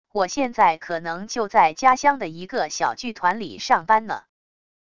我现在可能就在家乡的一个小剧团里上班呢wav音频生成系统WAV Audio Player